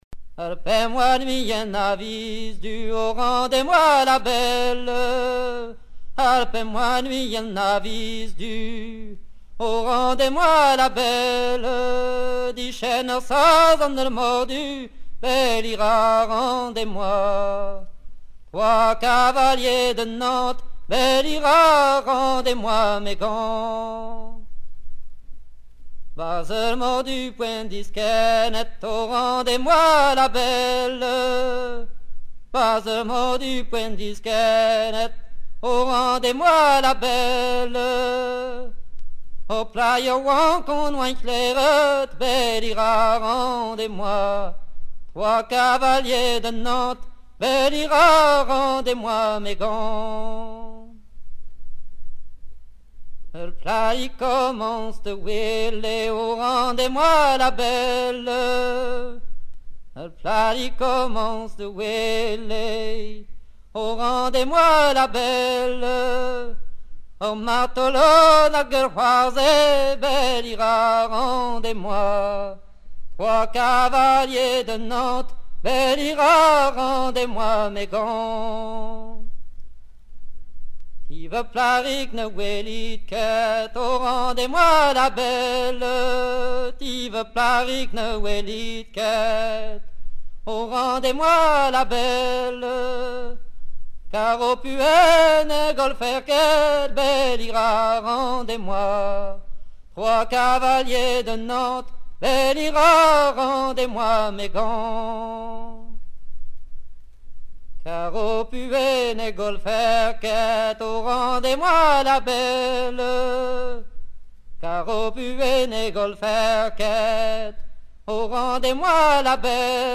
Sa version sert à soutenir le bal (ou tamm-kreiz) de la suite gavotte, mais elle est interprétée ici en mélodie
Pièce musicale éditée